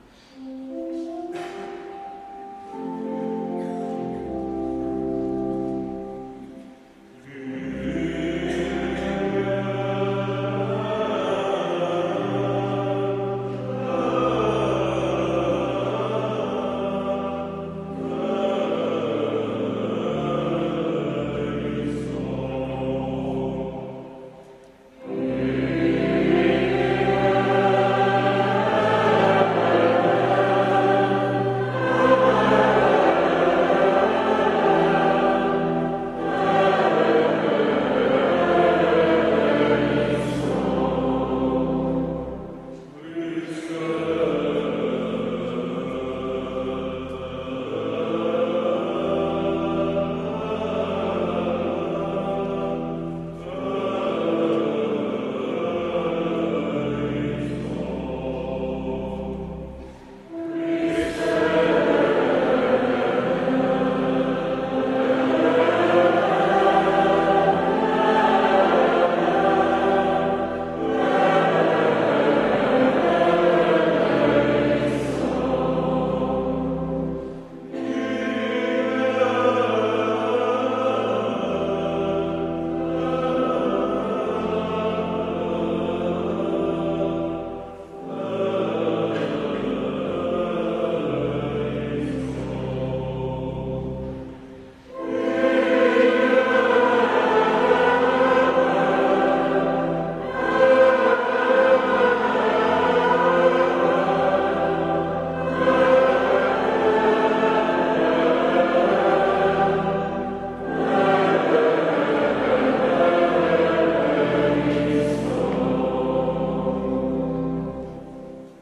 Kościół p.w. M.B. Różańcowej w Lublinie, ul. Bursztynowa 20
Godz. 10.00 – Msza Św.
oprawa muzyczna chórów parafialnych.